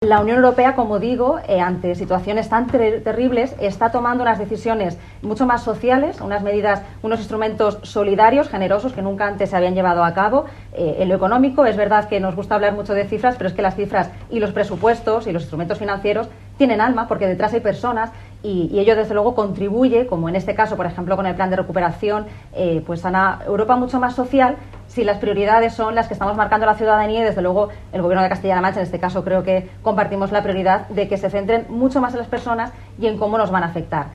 Además la directora general de Asuntos Europeos de Castilla-La Mancha, Virginia Marco, explicó que el Fondo Social Europeo (FSE) promueve la igualdad de oportunidades para todos sin discriminación, y de forma concreta la inclusión en la sociedad de las personas con discapacidad “en pie de igualdad con los demás”.
CorteVirginiaMarco.mp3